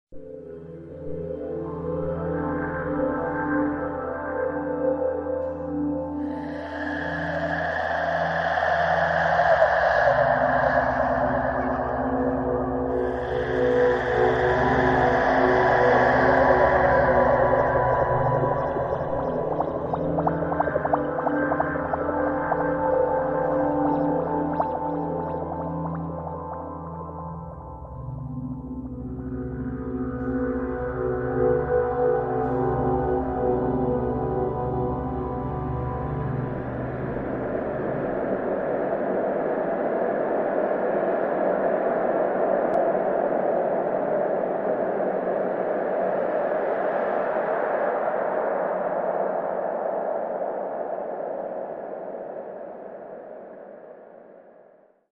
(tectonic dark ambient)